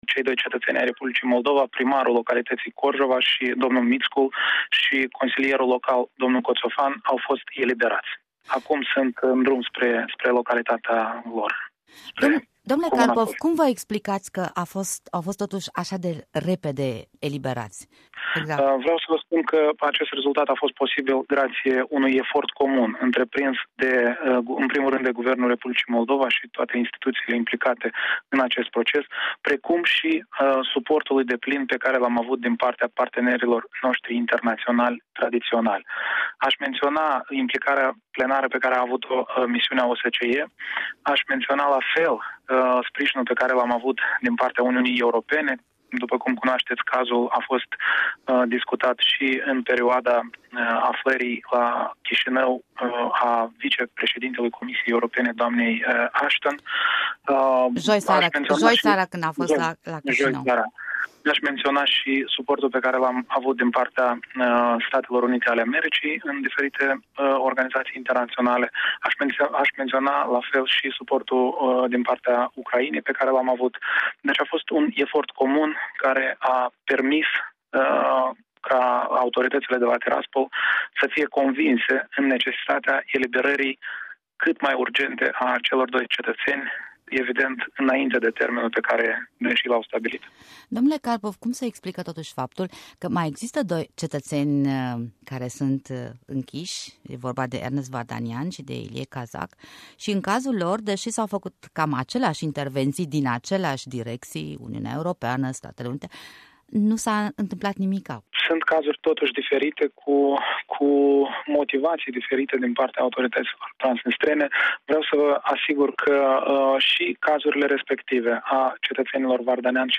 Interviul acordat Europei Libere de vicepremierul Eugen Carpov